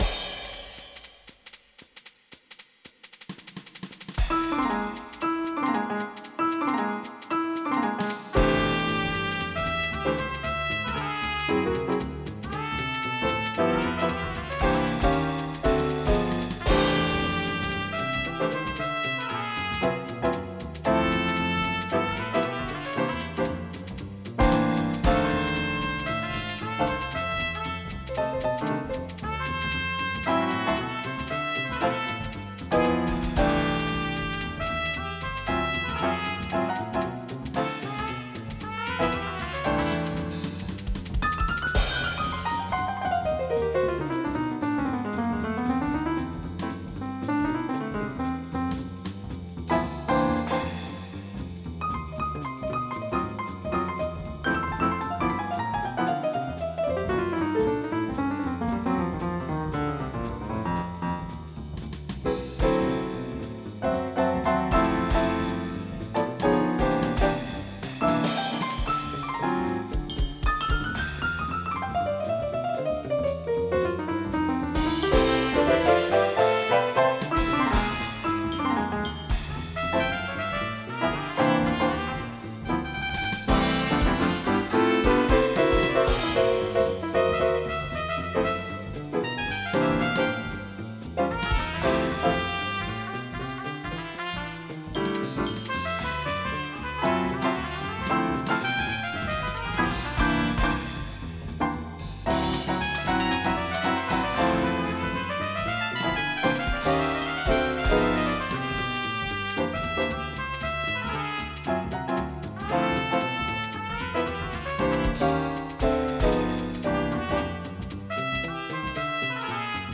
Jazz
MIDI Music File
Type General MIDI